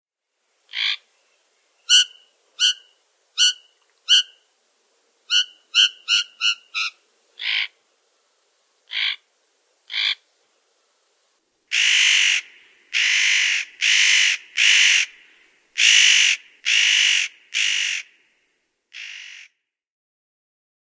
Ruf des Tannenhähers [1.061 KB] – mp3